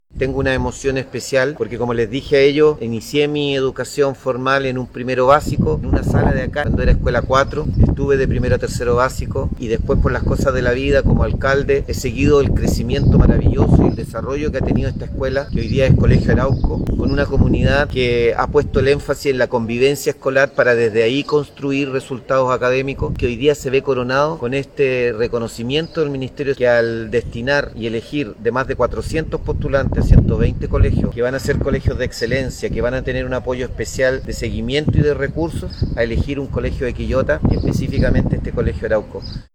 01-ALCALDE-Colegio-de-Excelencia.mp3